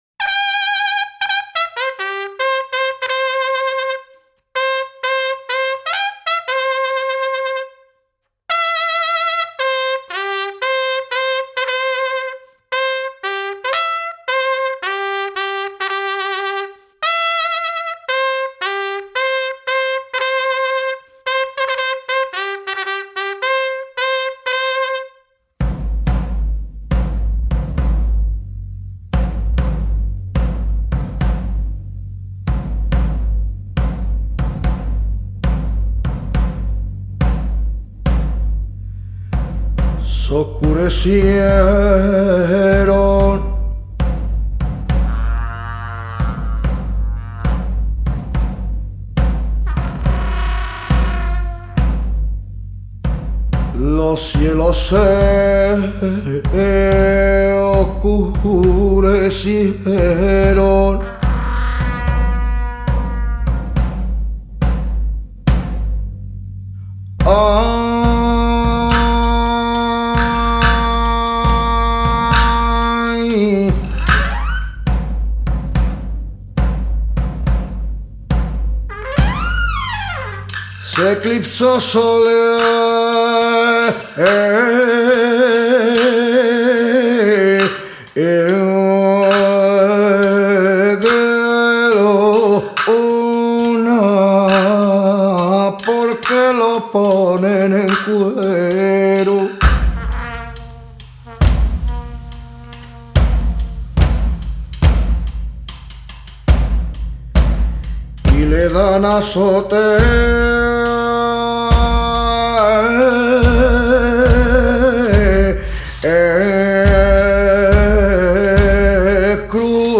clairon
batterie